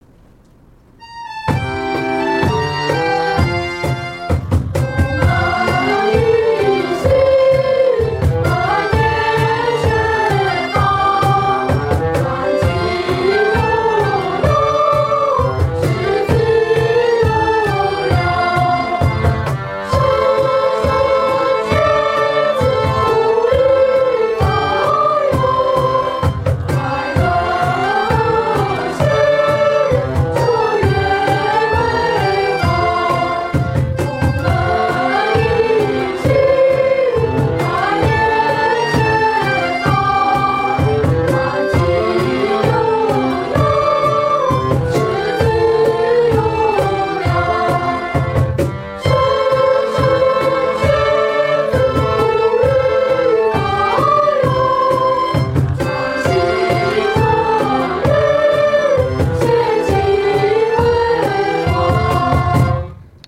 東門國小校歌(輕快版)